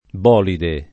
bolide [ b 0 lide ] s. m.